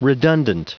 Prononciation du mot redundant en anglais (fichier audio)
Prononciation du mot : redundant